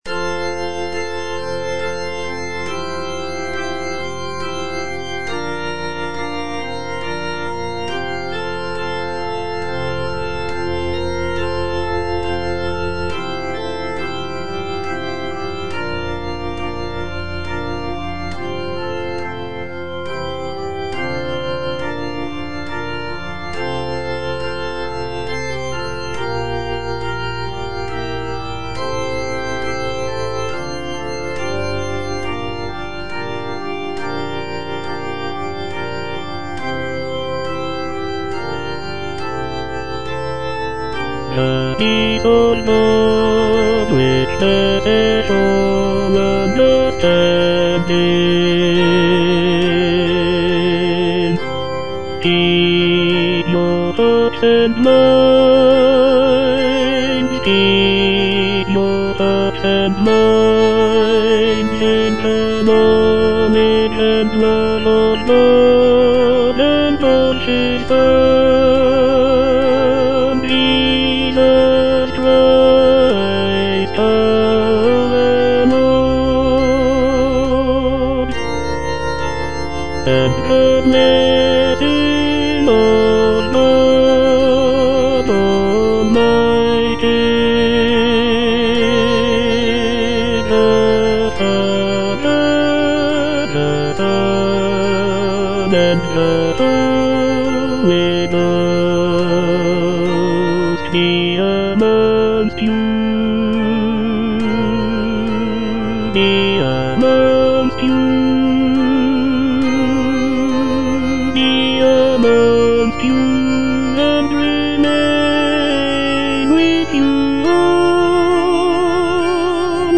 Tenor (Voice with metronome)